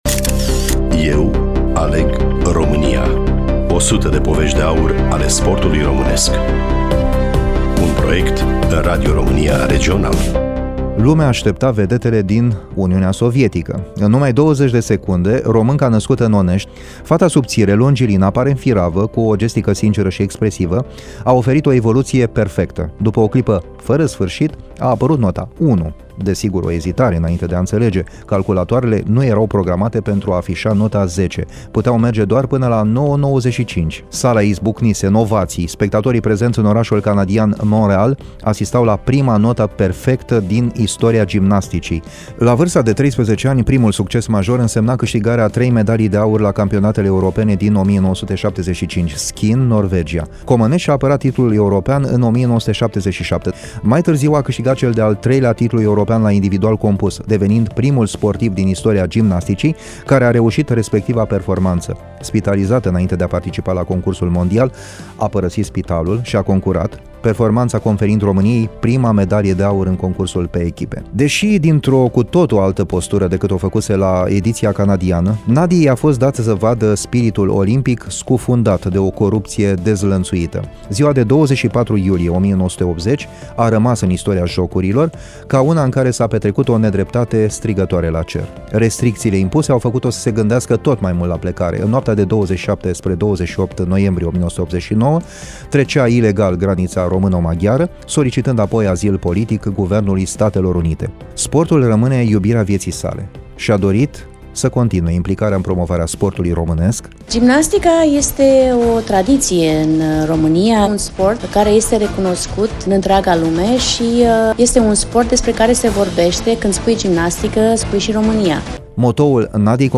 Studioul Radio Romania Iasi
NADIA-COMANECI-RADIO-IASI-AUDIO-FINAL.mp3